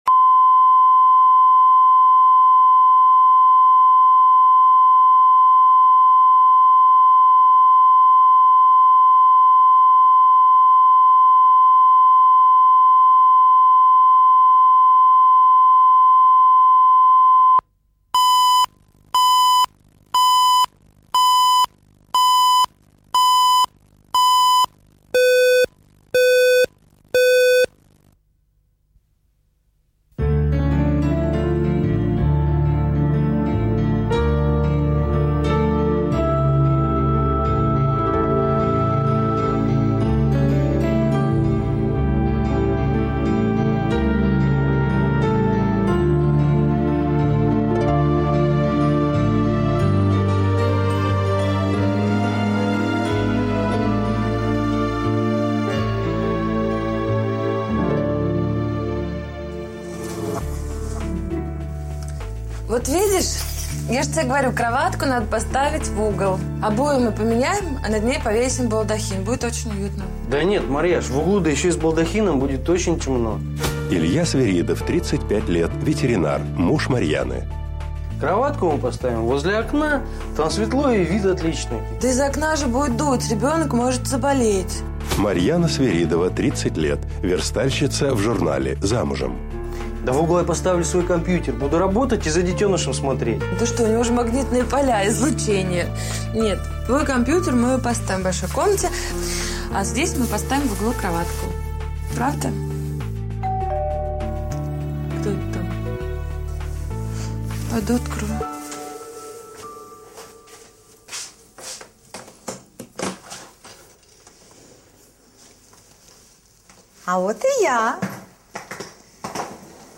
Aудиокнига Не ждали Автор Александр Левин.